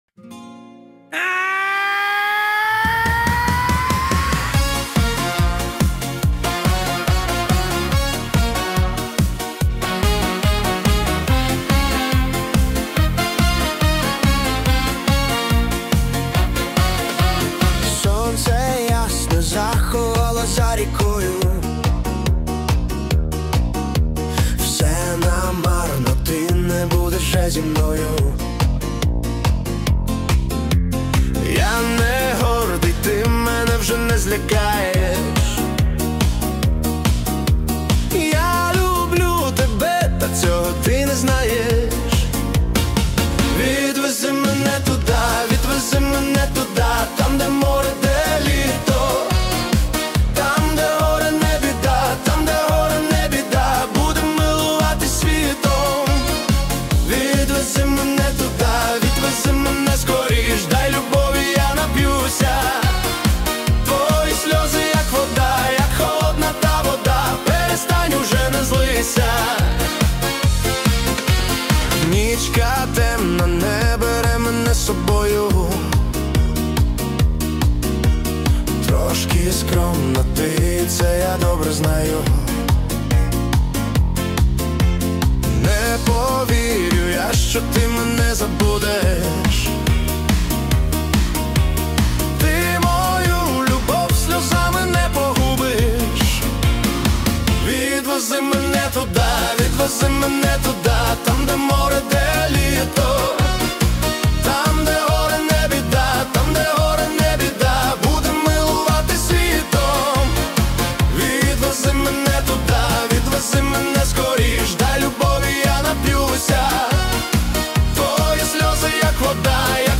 Всі мінусовки жанру AІ music
Плюсовий запис